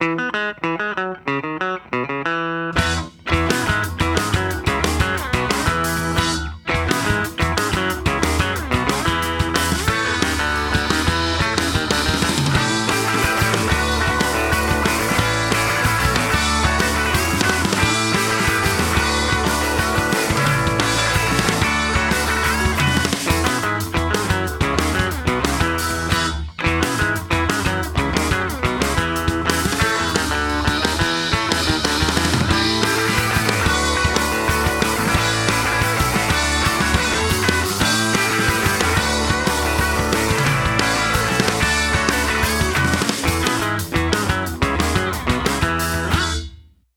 Instrumental punk rock
punk rock See all items with this value